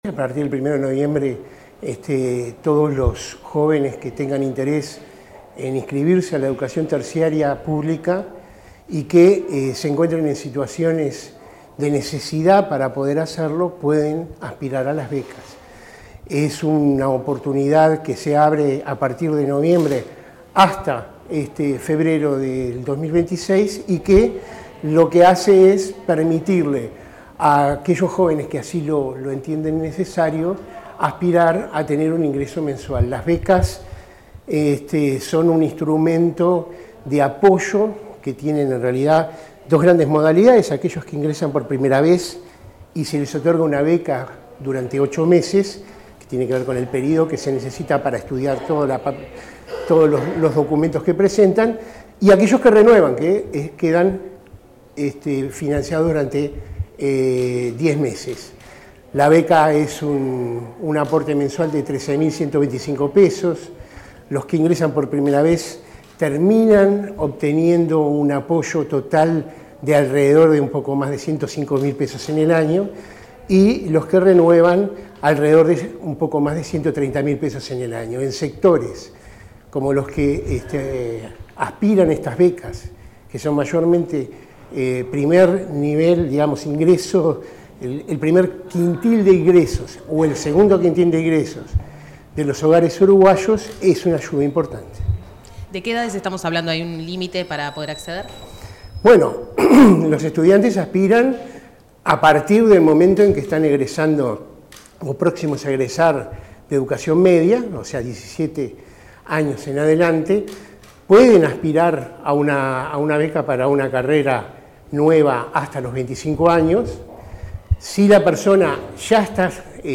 Declaraciones del presidente del Fondo de Solidaridad, Gabriel Errandonea